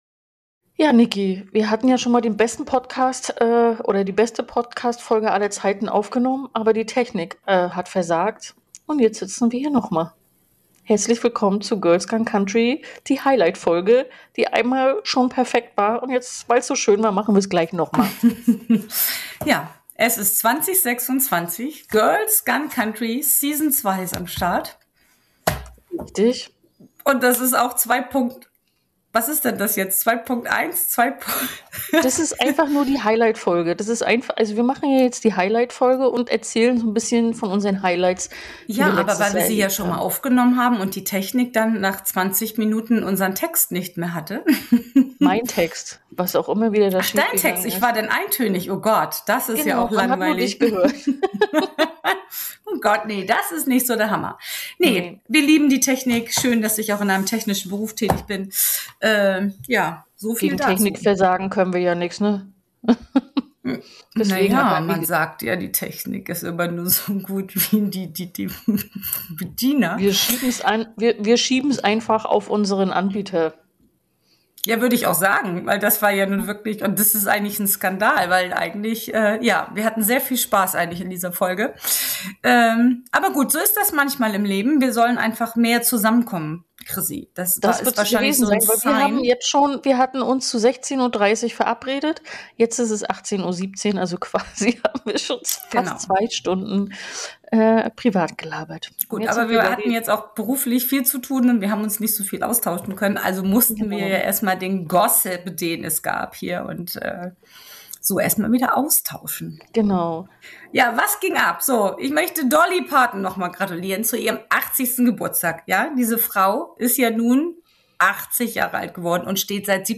Was euch hier erwartet, ist kein sauber durchgeplanter Rückblick, sondern ein Gespräch, das genauso läuft, wie es laufen muss. Abschweifend, laut, herzlich, manchmal albern, manchmal plötzlich tief - eben Girls Gone Country. Wir reden über ein Jahr, das komplett eskaliert ist. Über Country als Lebensgefühl und nicht als Playlist.